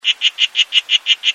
Los machos utilizan distintos tipos de llamadas:
Canto: